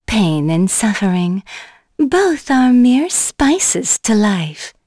Shamilla-Vox_Skill1.wav